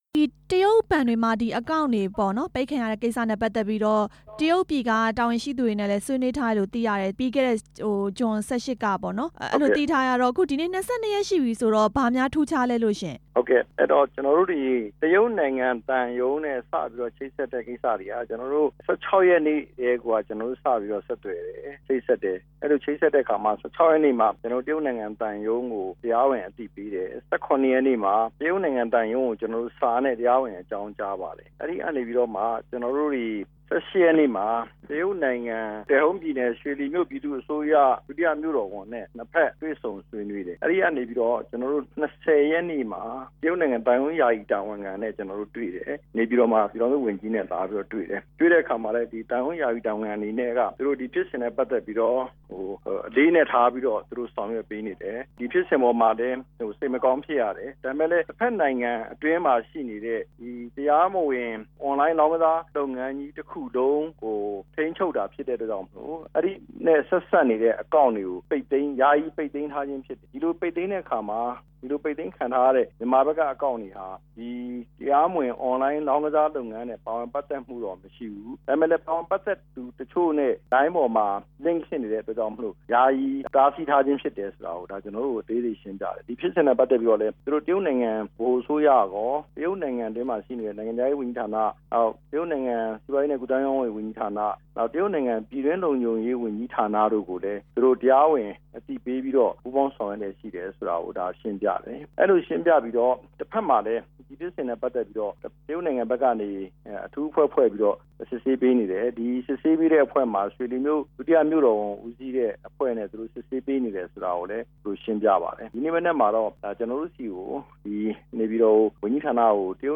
မြန်မာကုန်သည် ဘဏ်စာရင်း တရုတ်ပိတ်သိမ်းထားမှု မေးမြန်းချက်